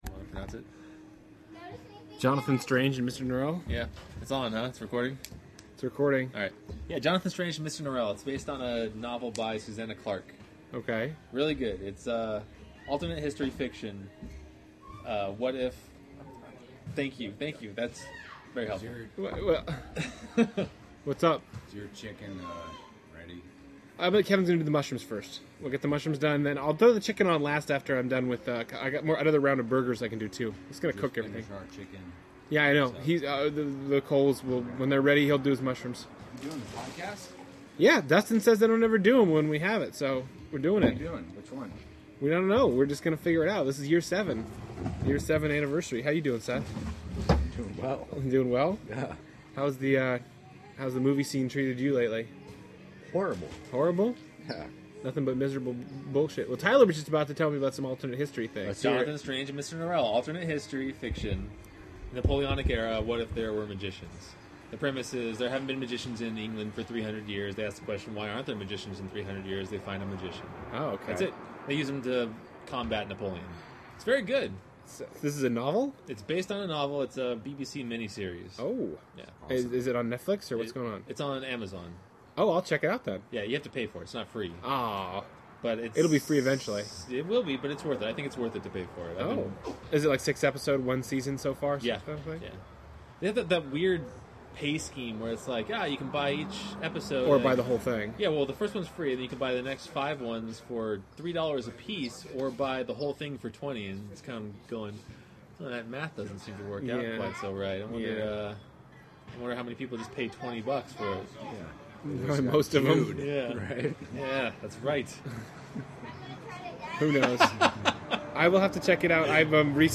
We talk about our love of film live at the Futile Podcast Year 7 Anniversary show.